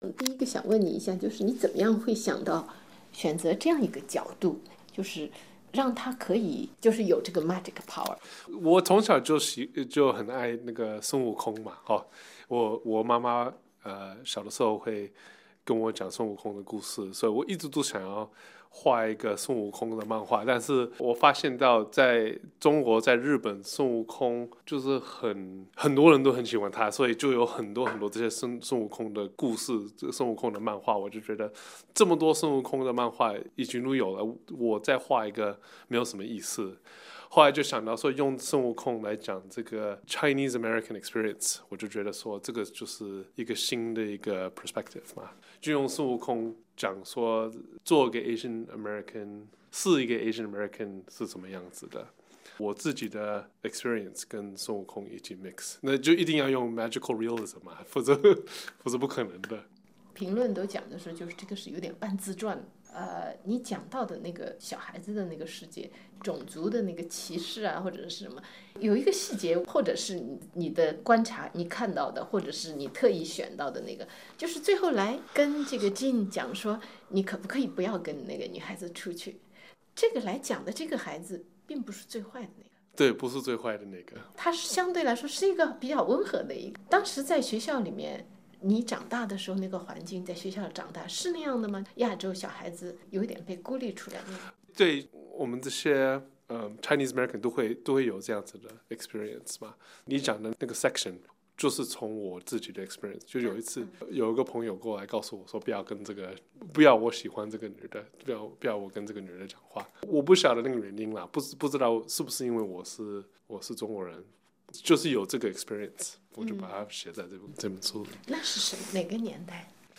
喜欢孙悟空的孩子：采访《美生中国人》作者杨谨伦